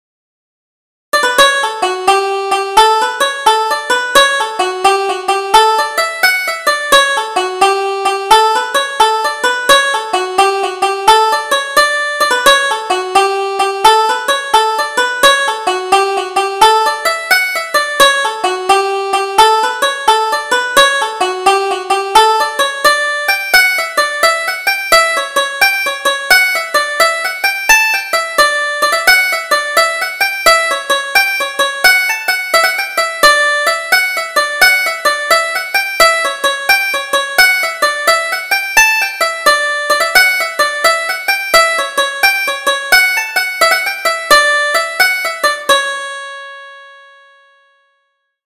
Double Jig: The Boys of Ballinamore